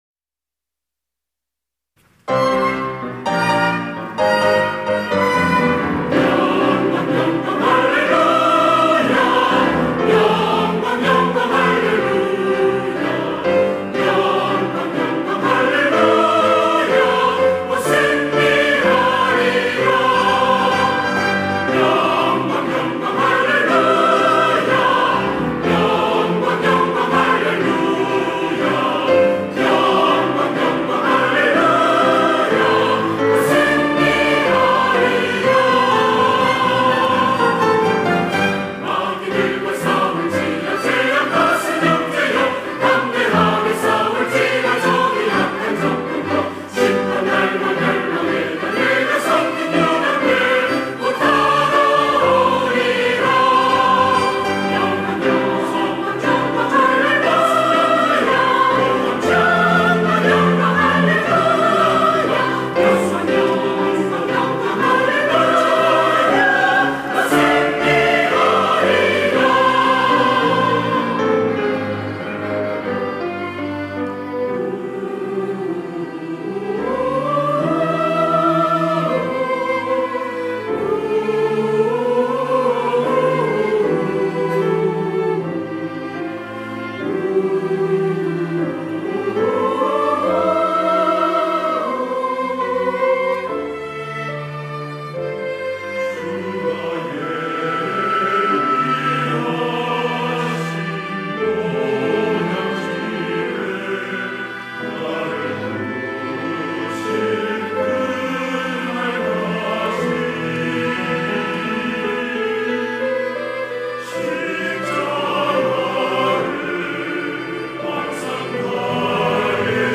할렐루야(주일2부) - 영광 할렐루야
찬양대 할렐루야